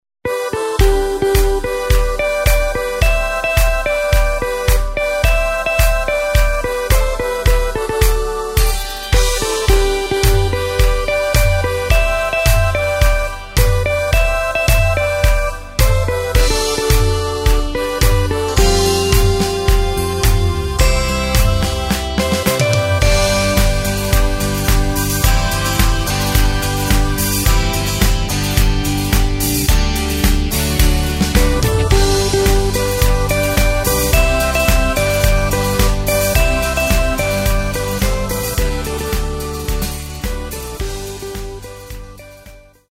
Takt:          4/4
Tempo:         108.00
Tonart:            G
Playback mp3 Demo